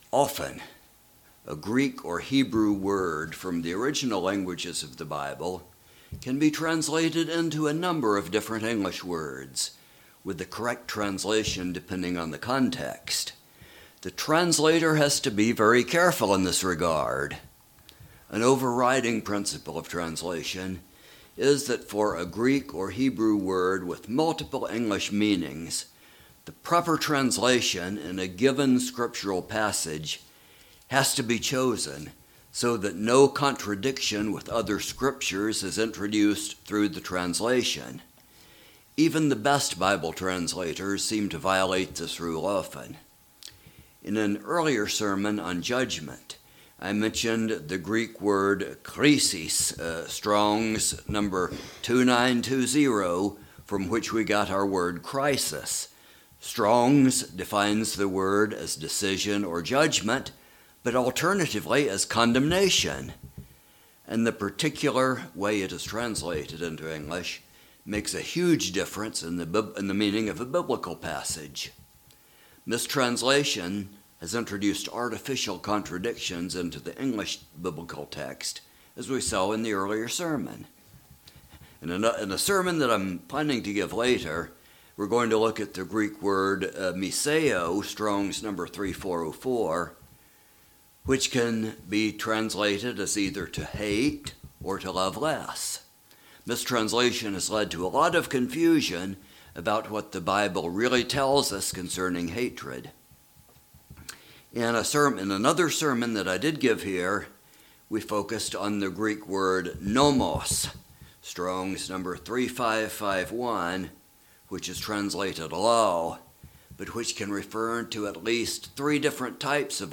Given in Roanoke and Kingsport on Sabbath, 7/30/2022